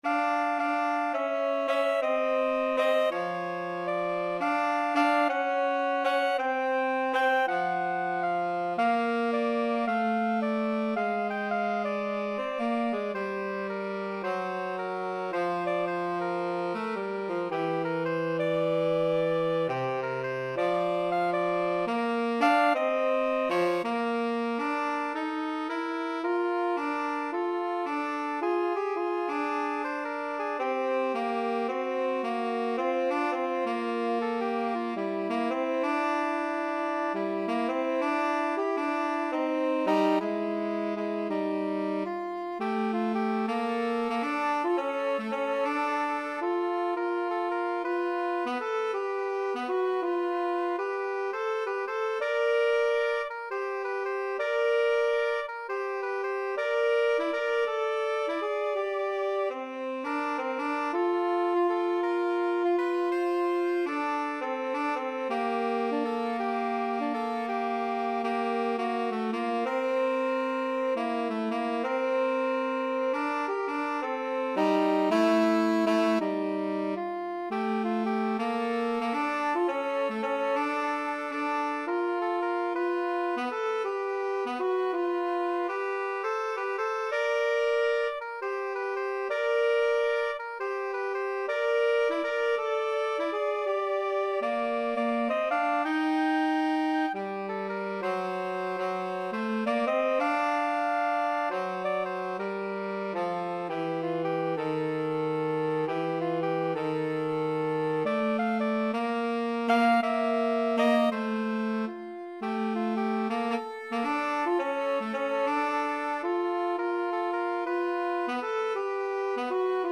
Clarinet/Soprano SaxTenor Saxophone
~ = 110 Moderate swing